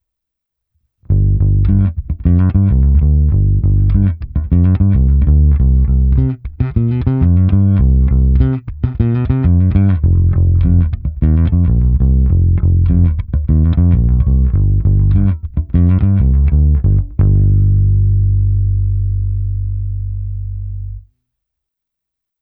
V řetězci dále byl aktivní preamp Darkglass Harmonic Booster, kompresor TC Ellectronic SpectraComp a preamp Darkglass Microtubes X Ultra se zapnutou simulací aparátu.
Cívka 1 - zvuk ala Precision - basy +50, středy 0, výšky +30